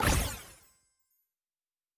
Special & Powerup (48).wav